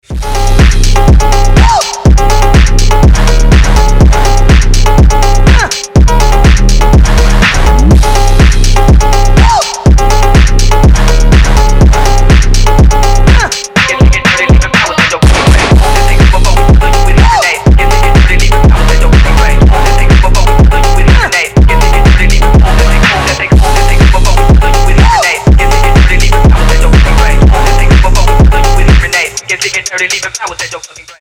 • Качество: 320, Stereo
мощные басы
качающие
фонк
Крутой качающий рингтон